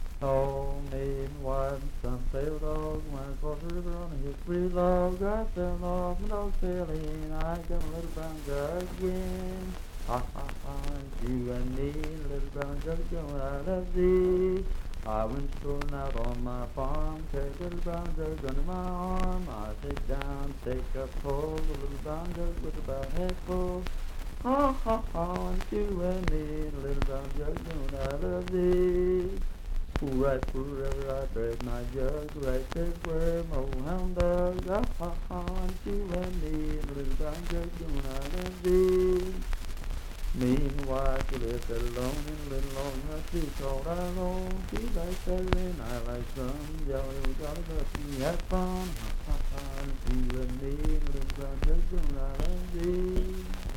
Unaccompanied vocal music
Dance, Game, and Party Songs
Voice (sung)
Pendleton County (W. Va.), Franklin (Pendleton County, W. Va.)